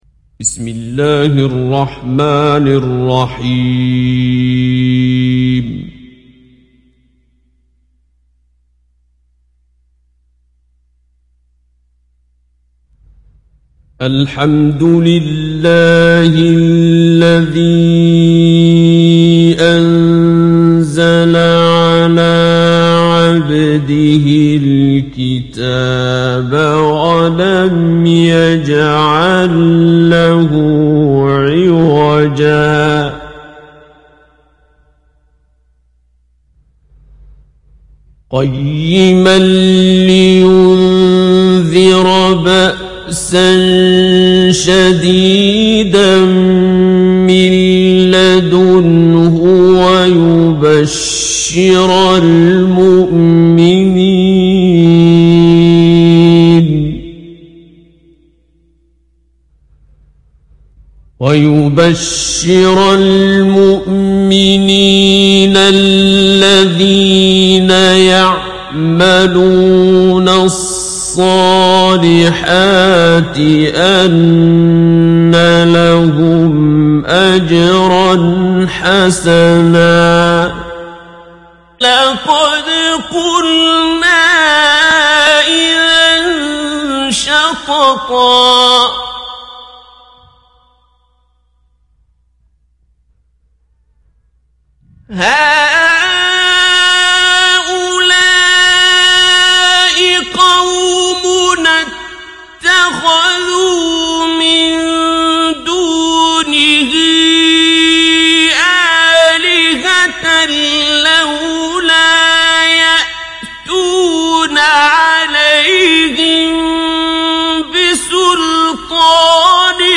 Қуръони карим тиловати, Қорилар. Суралар Qur’oni karim tilovati, Qorilar. Suralar